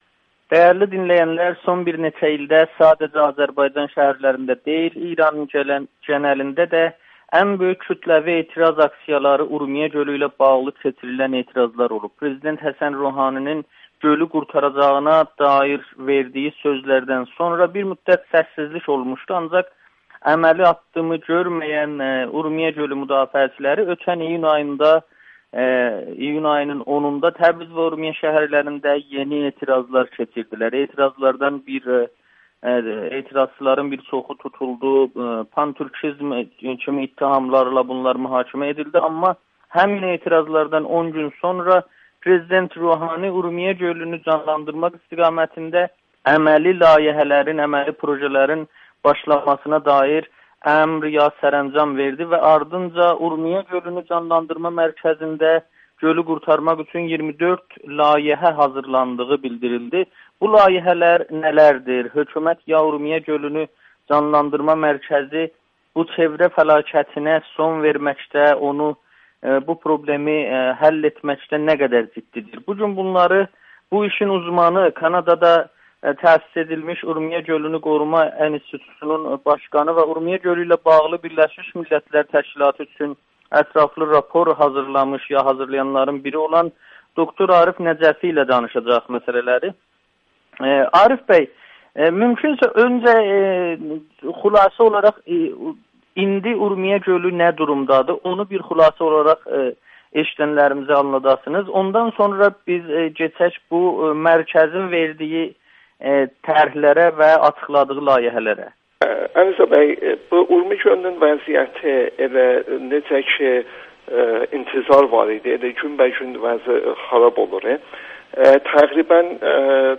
Ətraf mühiti idarəetmə eksperti Amerikanın Səsi ilə söhbətdə Tehranın Urmiyə Gölü ilə bağlı layihələrini dəyərləndirir